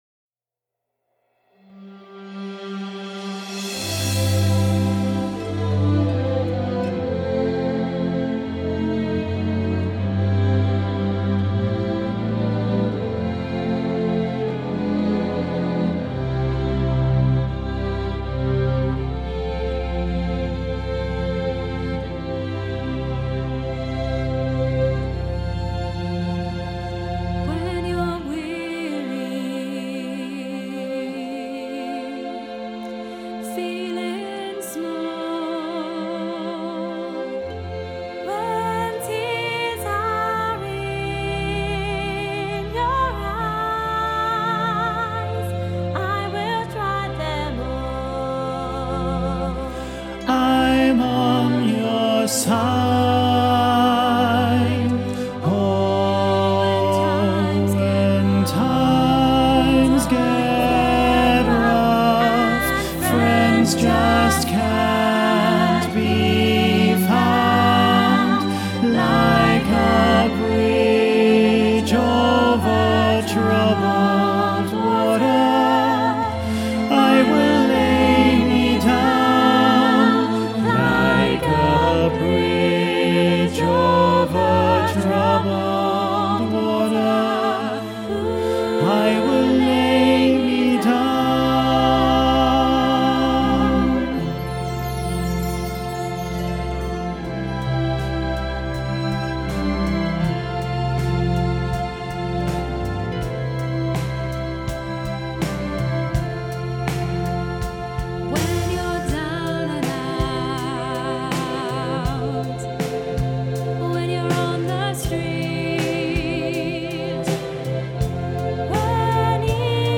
Listen to bass track with soprano and alto accompaniment
bridge-over-troubled-water-bass-half-mix.mp3